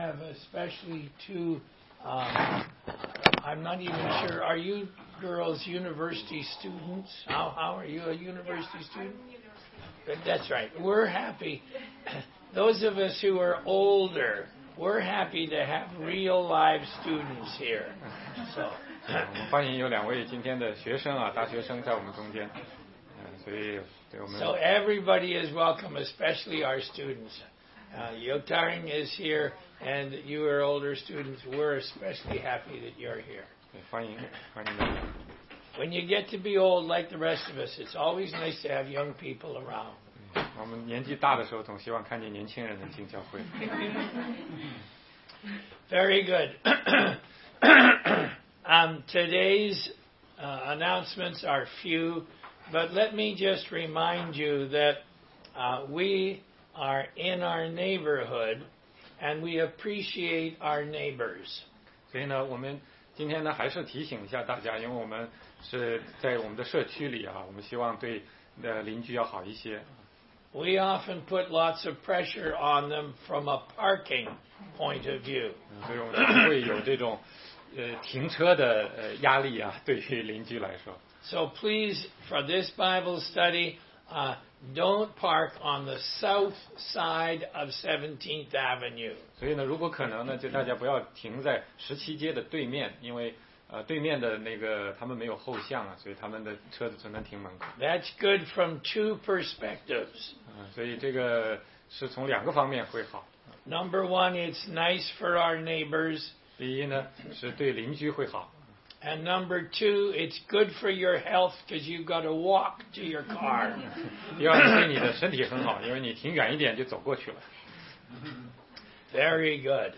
16街讲道录音 - 罗马书10章1-17节：信主的道离你不远，就在你的口里和心里